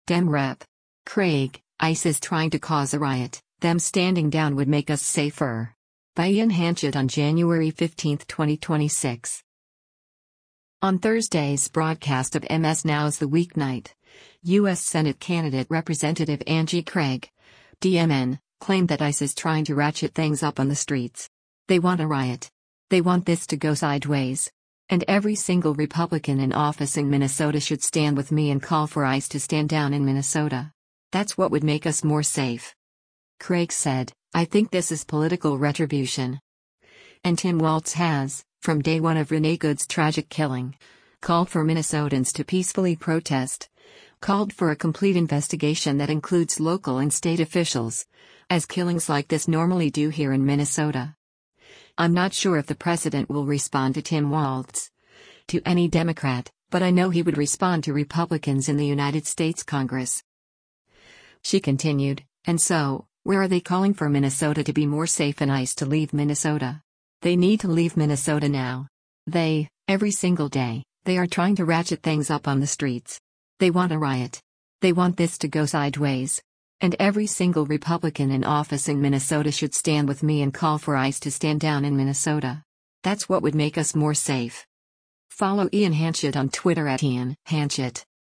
On Thursday’s broadcast of MS NOW’s “The Weeknight,” U.S. Senate candidate Rep. Angie Craig (D-MN) claimed that ICE is “trying to ratchet things up on the streets.